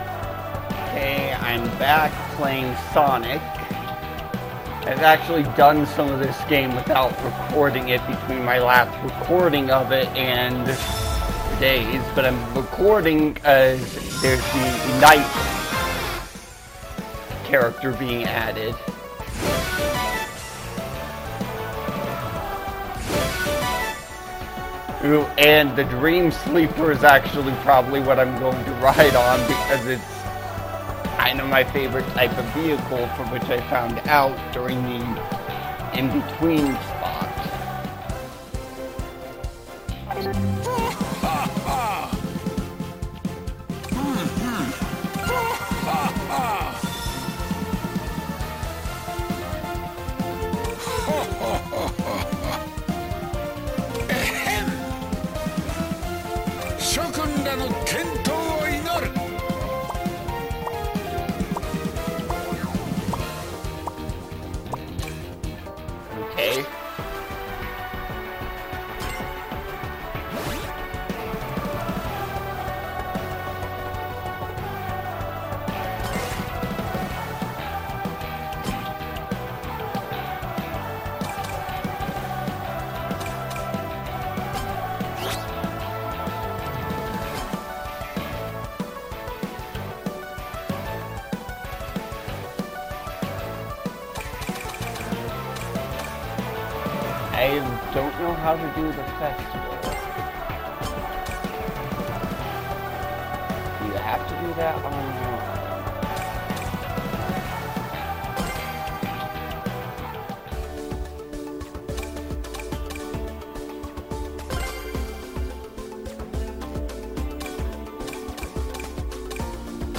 I play Sonic Racing Crossworlds with commentary